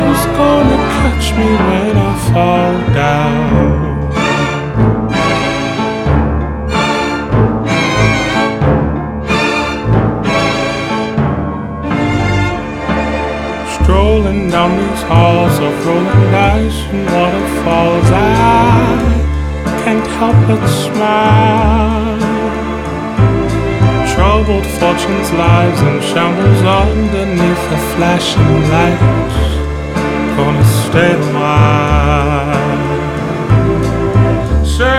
Жанр: R&b / Соул